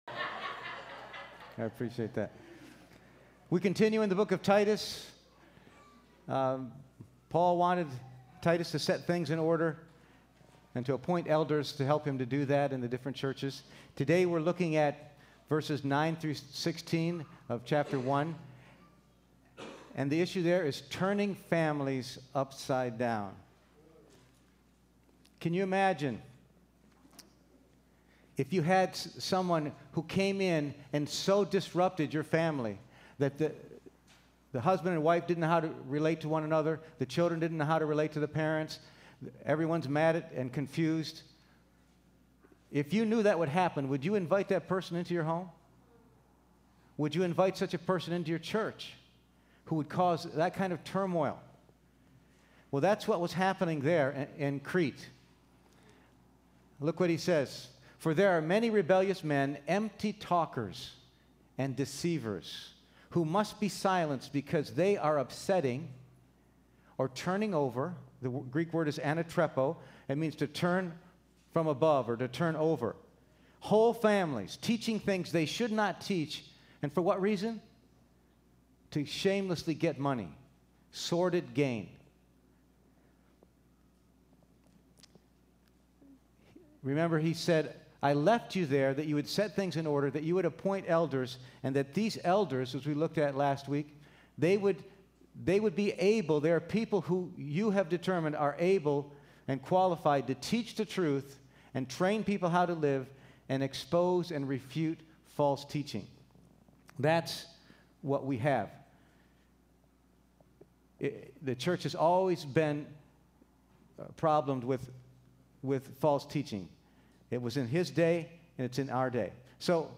Titus 1:9-16 Service Type: Sunday Morning %todo_render% « Elders